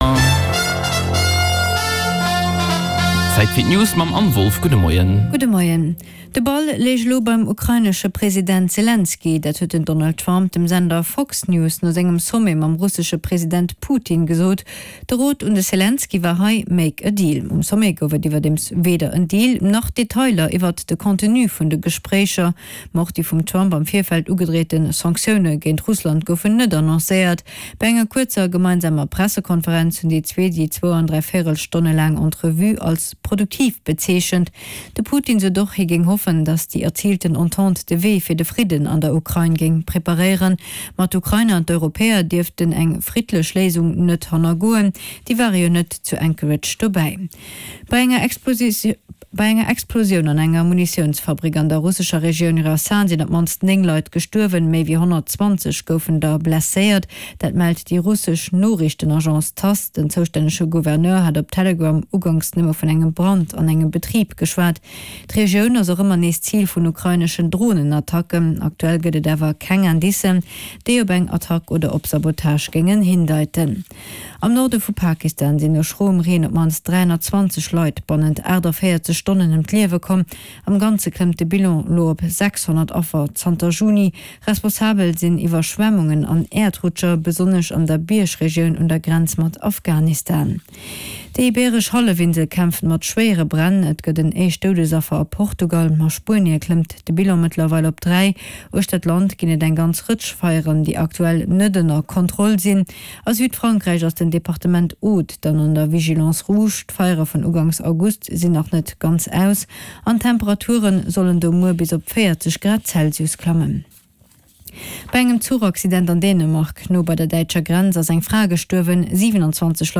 Play Rate Listened List Bookmark Get this podcast via API From The Podcast Den News Bulletin mat allen Headlines aus Politik, Gesellschaft, Economie, Kultur a Sport, national an international Join Podchaser to...